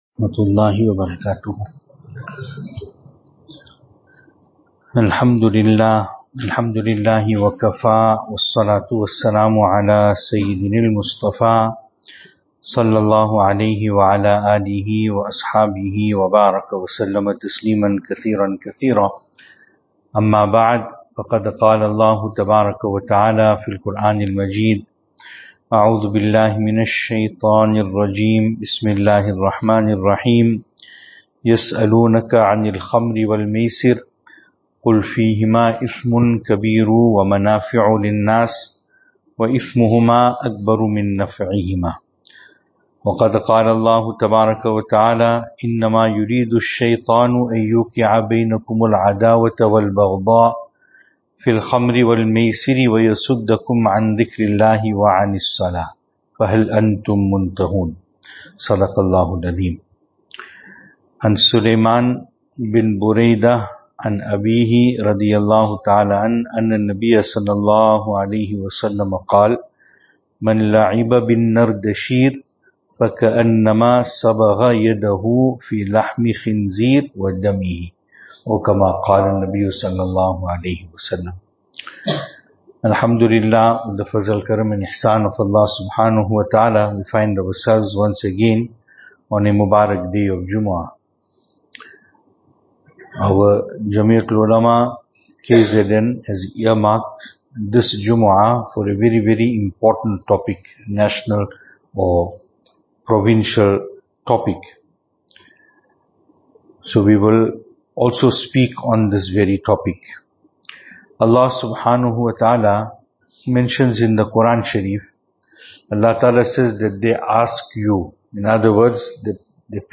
2025-12-05 Gambling in all it’s forms is Haraam Venue: Albert Falls , Madressa Isha'atul Haq Series: JUMUAH Service Type: Jumu'ah Topics: JUMUAH « The Family of Nabi e Kareem Sallallahu Alaihi Wasallam.